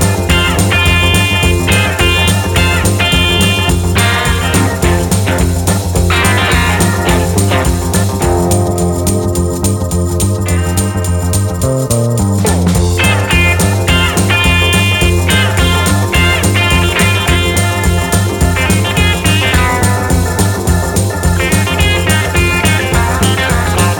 No Guitars Pop (1960s) 2:41 Buy £1.50